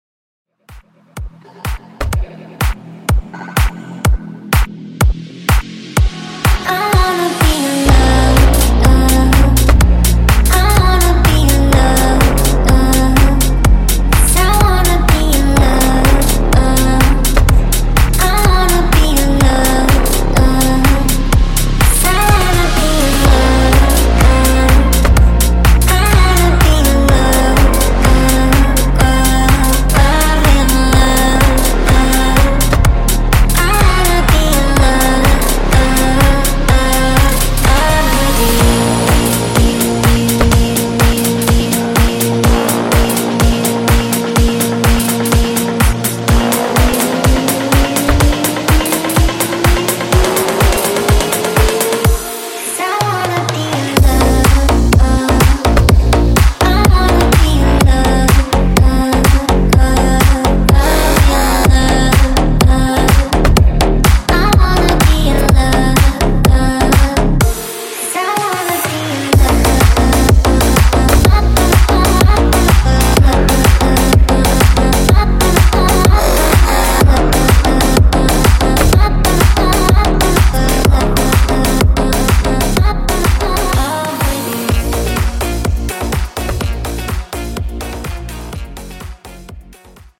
DEEP HOUSE , DRUM AND BASS
Clean